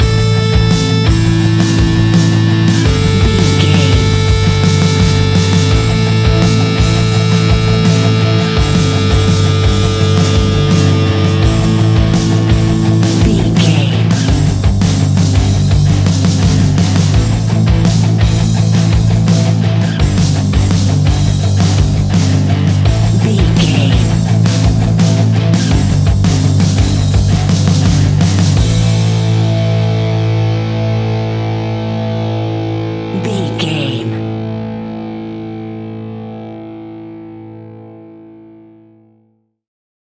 Epic / Action
Dorian
hard rock
blues rock
instrumentals
Rock Bass
Rock Drums
heavy drums
distorted guitars
hammond organ